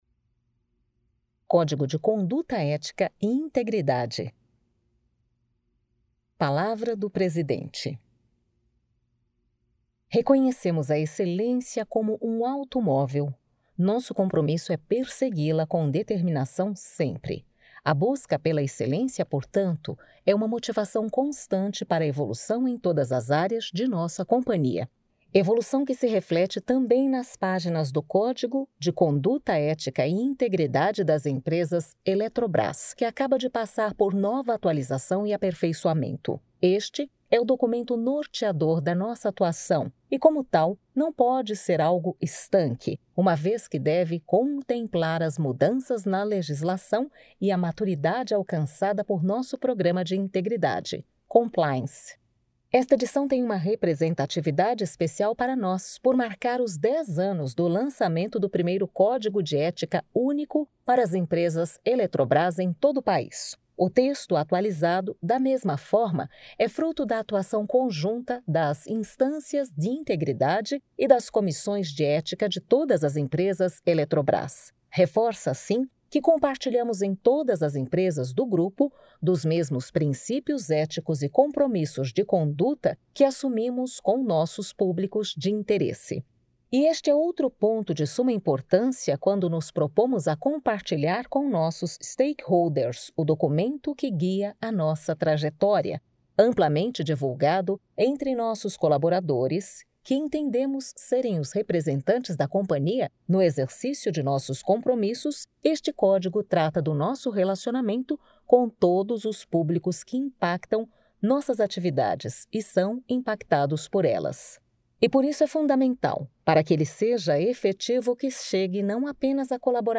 Versão em áudio Audiobook Código de Conduta Ética e Integridade 2020
Audiobook Código de Conduta Ética e Integridade 2020 COMPLETO.mp3